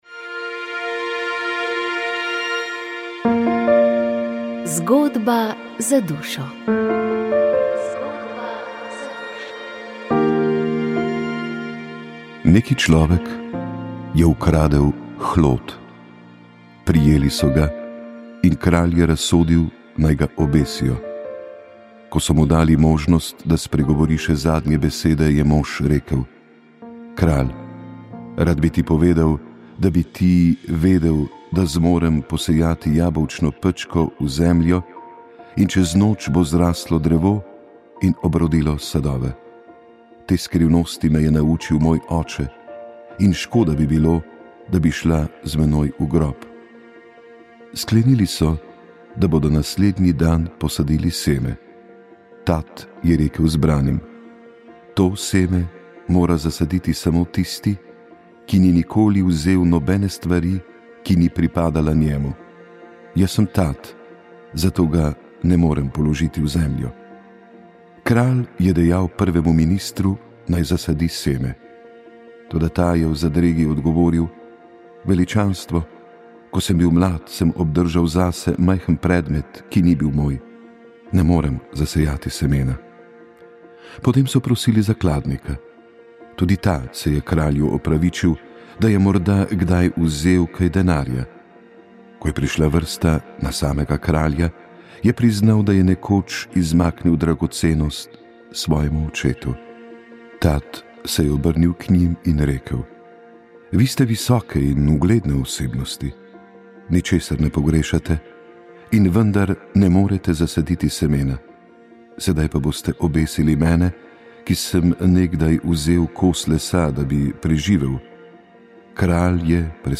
Tema pogovora pa podpora svojcem bolnikov z demenco. Spominčica ima na voljo celo vrsto dejavnosti in storitev, ki so svojcem lahko v pomoč in podporo: posvetovalnico, svetovalni telefon, skupine za samopomoč, usposabljanje za svojce, Alzheimer cafe, aktivno druženje in družabništvo.